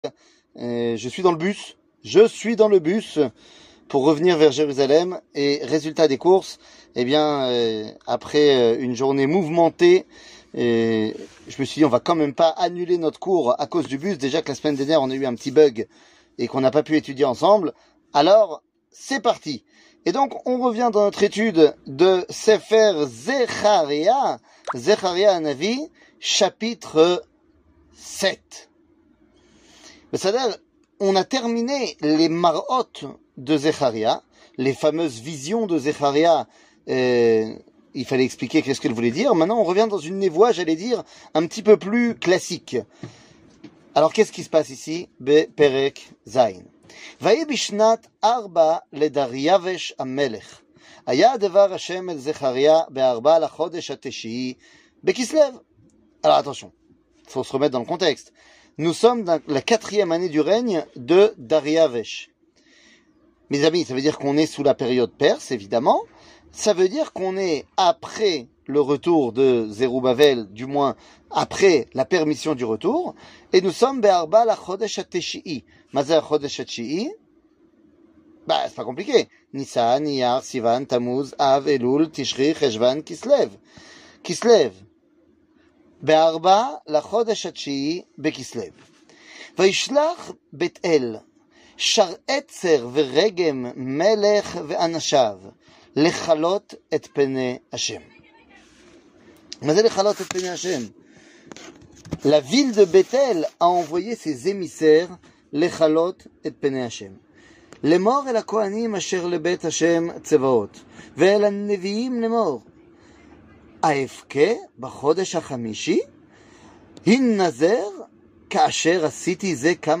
Les petits prophetes, 27, Zeharia (suite 3) 00:26:51 Les petits prophetes, 27, Zeharia (suite 3) שיעור מ 14 יוני 2022 26MIN הורדה בקובץ אודיו MP3 (24.58 Mo) הורדה בקובץ וידאו MP4 (65.53 Mo) TAGS : שיעורים קצרים